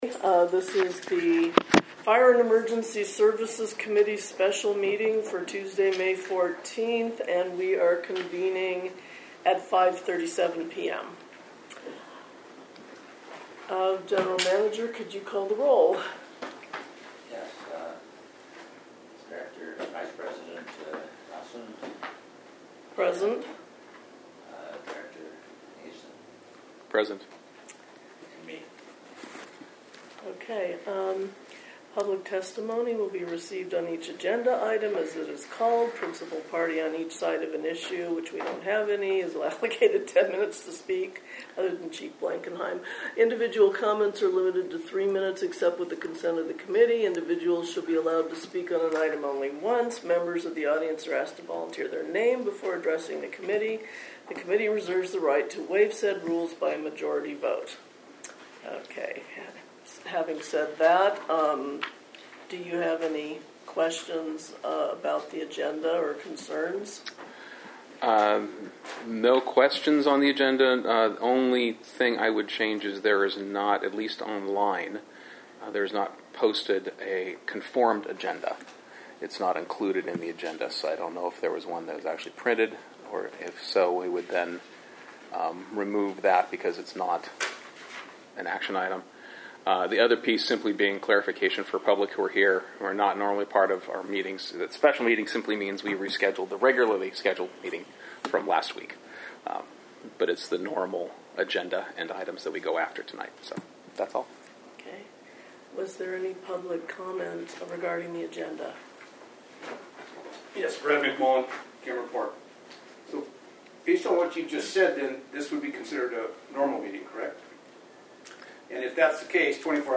Fire and Emergency Services Committee Special Fire & Emergency Services Committee Meeting